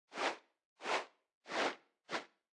Media:Sfx_Anim_Ultimate_Medic.wav 动作音效 anim 在广场点击初级、经典、高手、顶尖和终极形态或者查看其技能时触发动作的音效
Sfx_Anim_Classic_Medic.wav